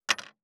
586魚切る,肉切りナイフ,まな板の上,
効果音厨房/台所/レストラン/kitchen食器食材
効果音